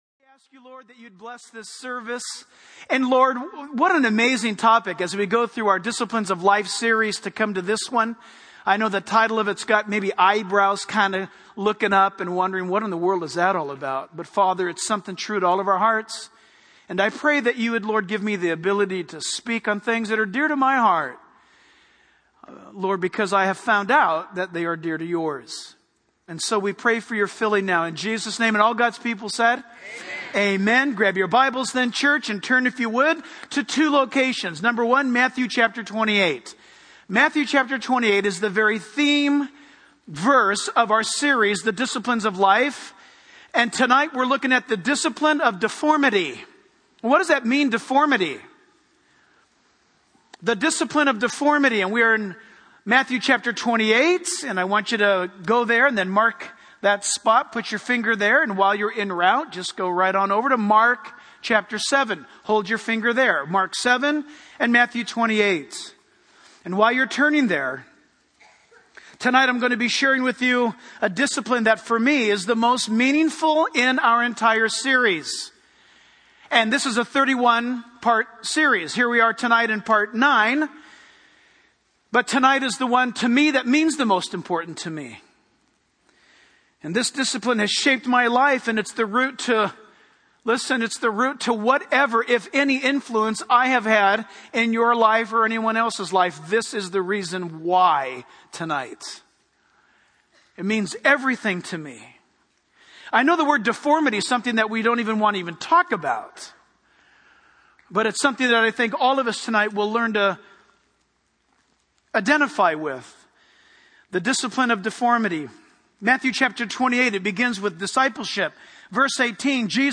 In this sermon, the preacher emphasizes the transformative power of God in changing lives.